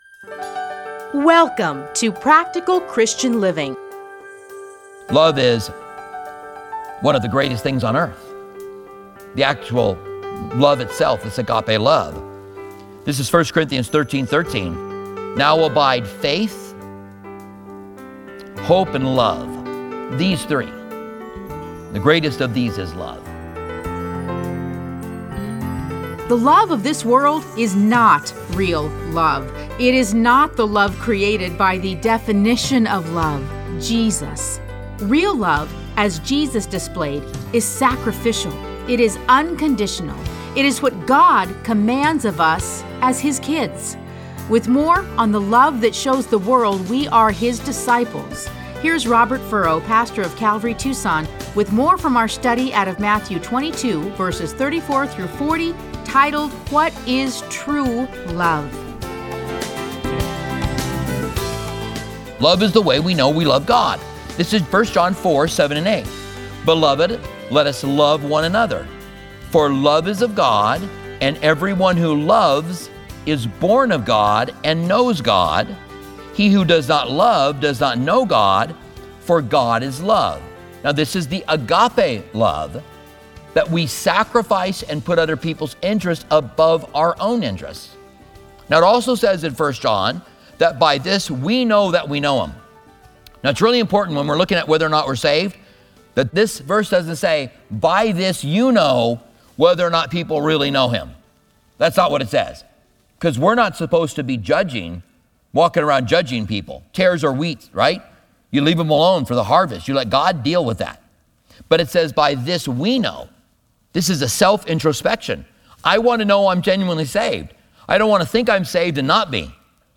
Listen to a teaching from Matthew 22:34-40.